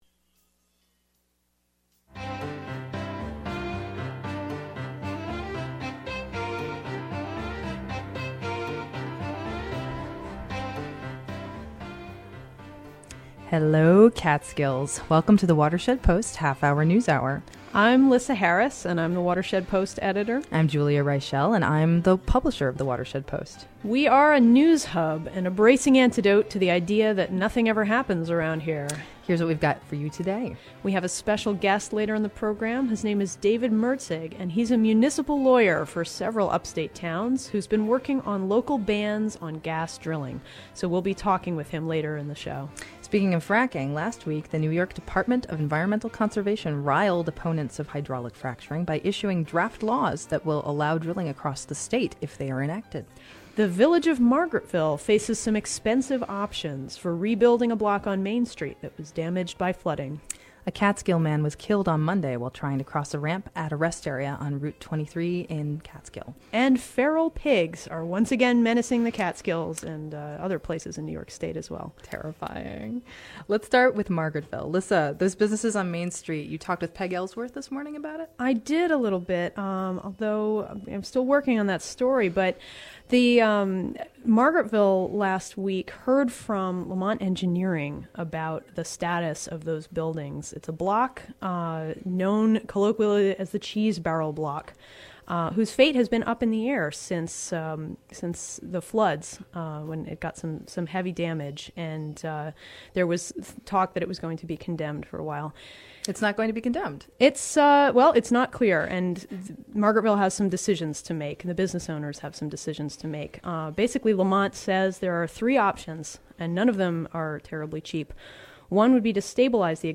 The Watershed Post Half-Hour News Hour airs live at 1 p.m. on Wednesdays on WIOX 91.3 FM in Delaware and Ulster counties and at 3 p.m. on Wednesdays on WGXC 90.7 FM in Greene and Columbia counties.